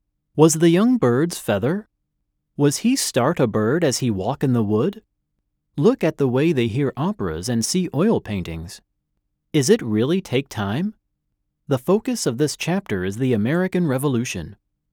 专业录音棚
数据堂TTS录音棚通过清华大学建筑环境检测中心检测，达到专业级NR15声学标准，混响时间小于0.1秒，背景噪音小于20dB(A)。
拥有专业级人声电容麦克风、Mackie Big Knob专业级监听设备、apollo声卡，支持8路输入。
美式英语，磁性男声